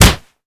kick_heavy_impact_05.wav